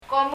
« grape 葡萄（ブドウ） cart カート » rubber ゴム komu [kɔmu] パラオ語には、kの音の濁音であるg音がないのかもしれません。